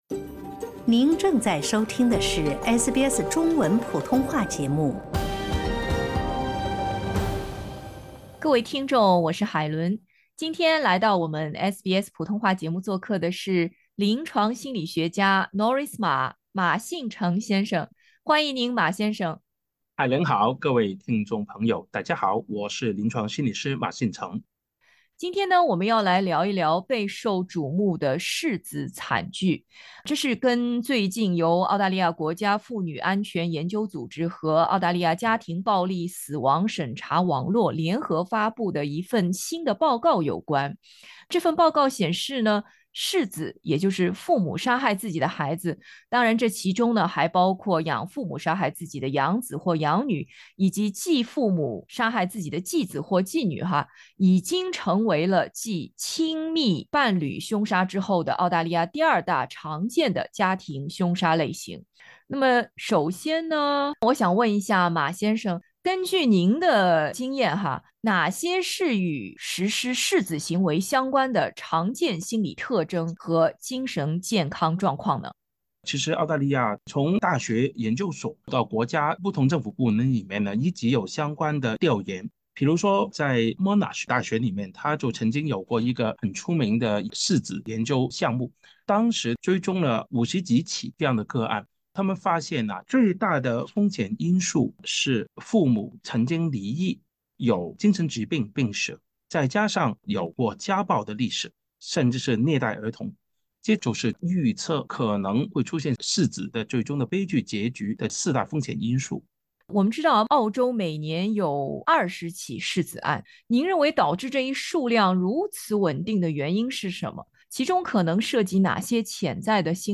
请点击音频，收听专家详解。